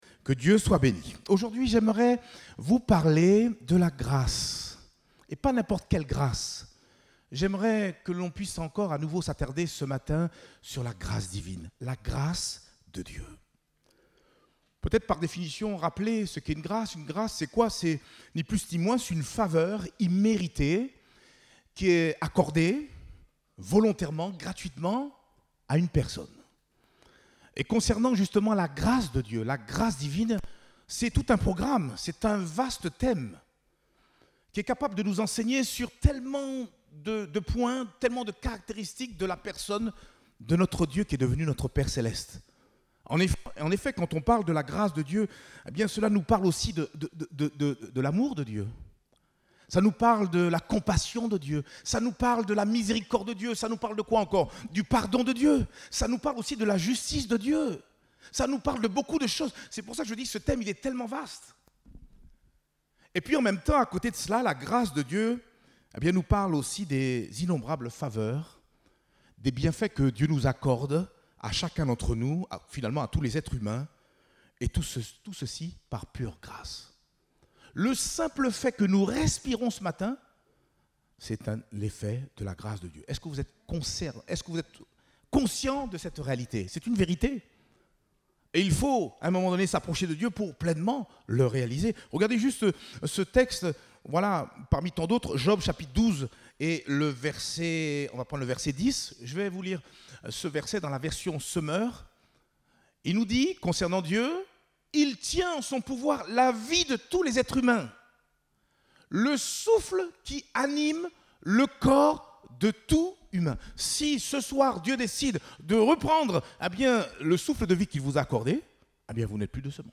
Date : 9 janvier 2022 (Culte Dominical)